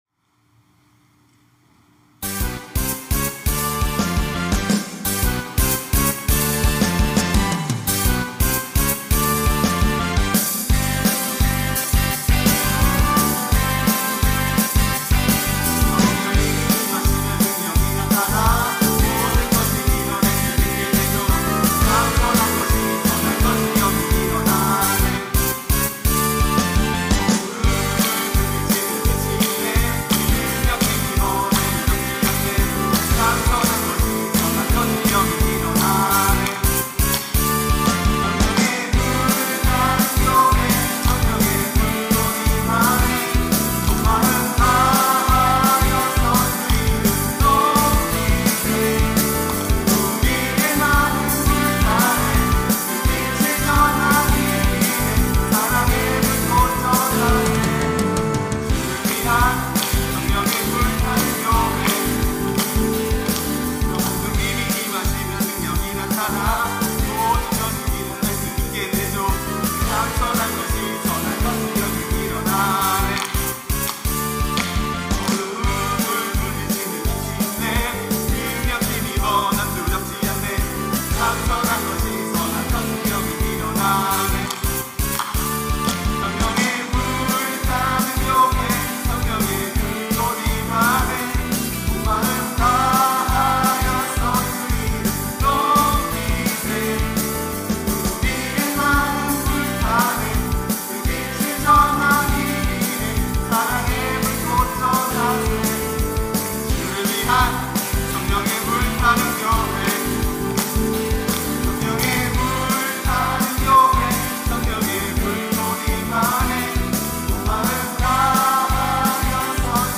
특송과 특주 - 성령의 불타는 교회
청년부 2025년 3팀 13셀